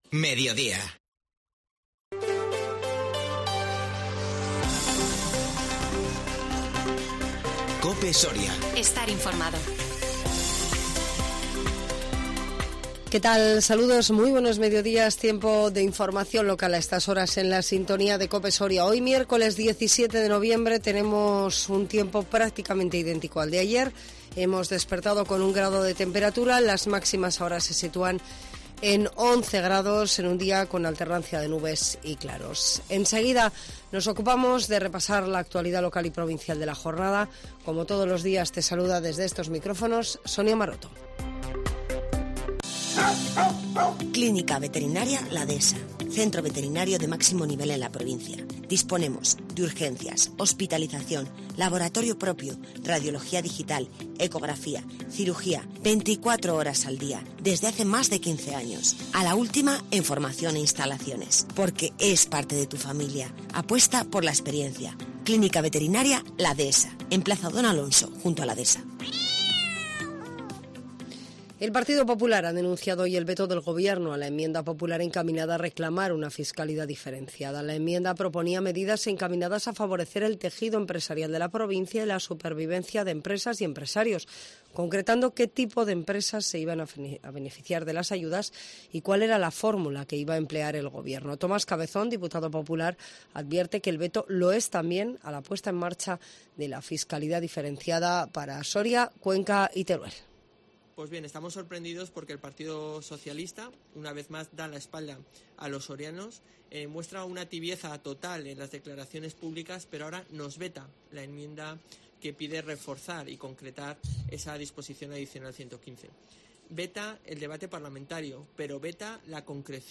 INFORMATIVO MEDIODÍA 17 NOVIEMBRE 2021